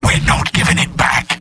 Robot-filtered lines from MvM. This is an audio clip from the game Team Fortress 2 .
{{AudioTF2}} Category:Sniper Robot audio responses You cannot overwrite this file.